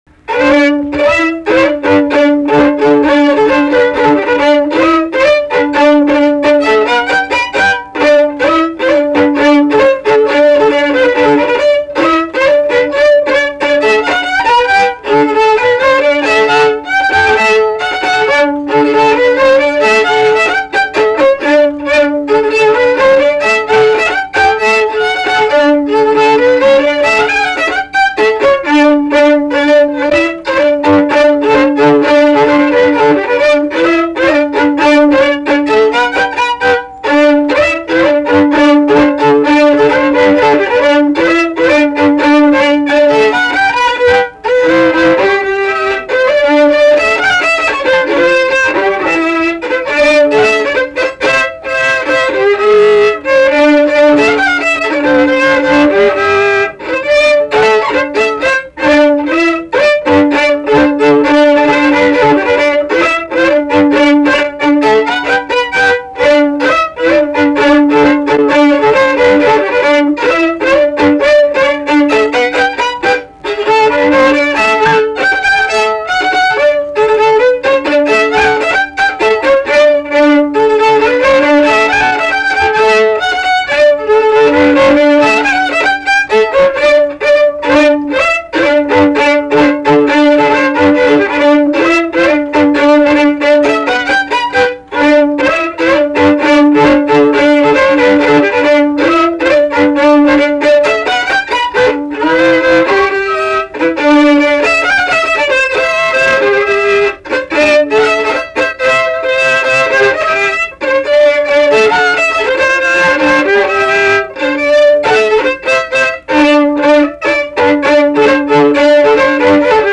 Polka
šokis